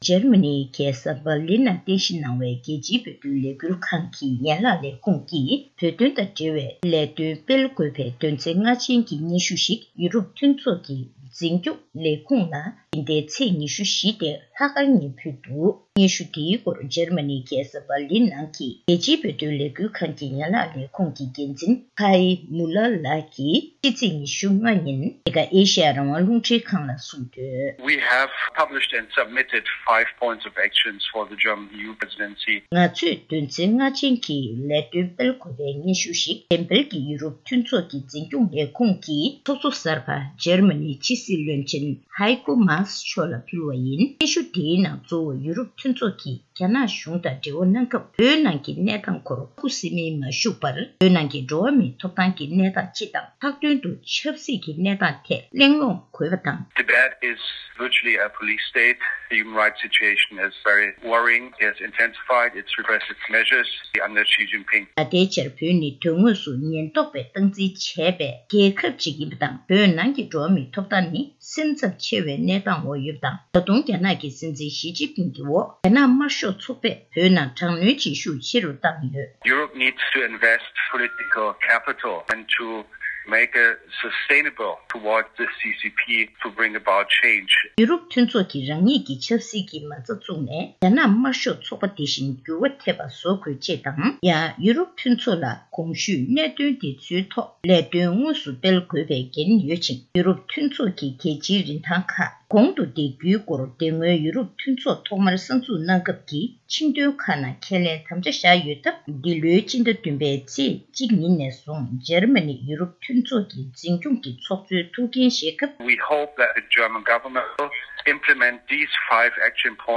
གསར་འགོད་པ